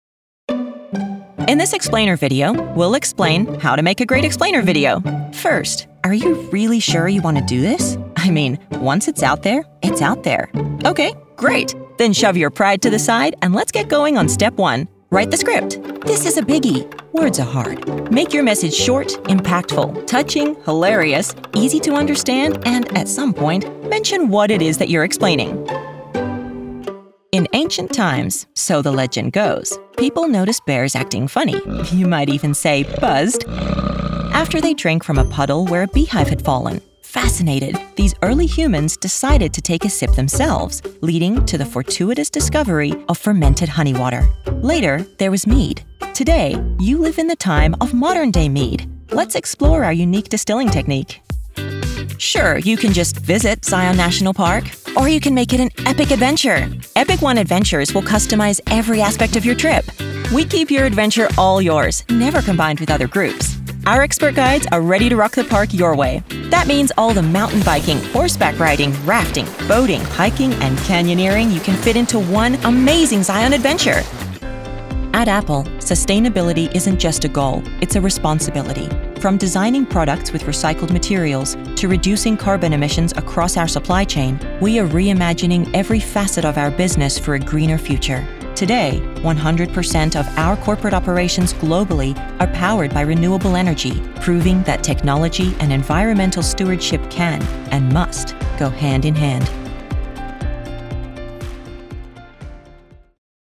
Warm, confident, nuanced, and with range from deep luxury to sarcastic milennial and everything in between.
Explainer Demo
General American, Neutral Canadian
Young Adult
Middle Aged